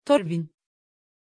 Pronunciation of Torwin
pronunciation-torwin-tr.mp3